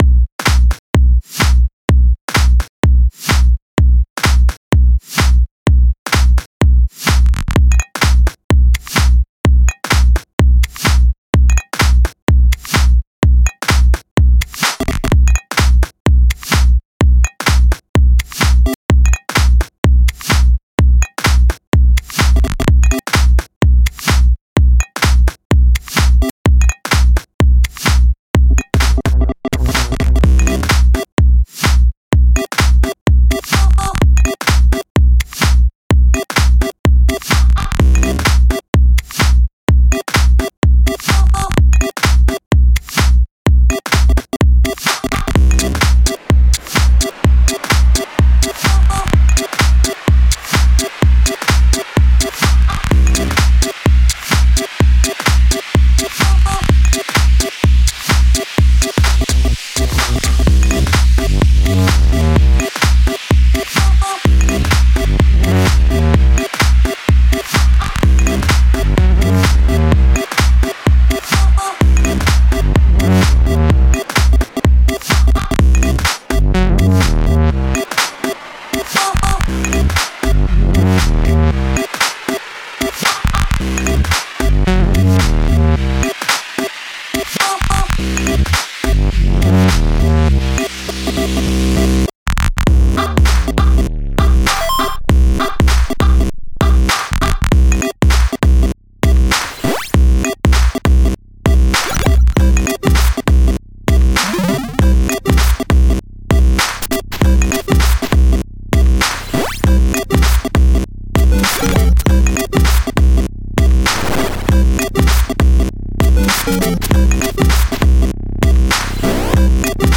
Жанр:House